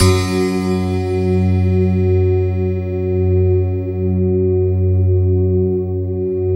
SYN_Piano-Pad1.wav